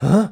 Male_Grunt_Curious_04.wav